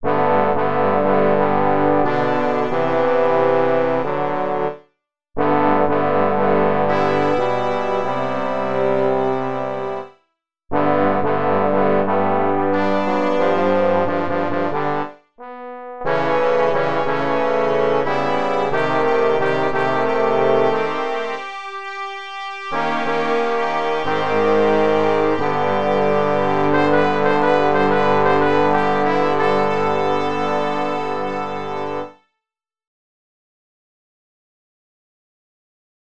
Key written in: G Major
How many parts: 4
Type: Barbershop
All Parts mix: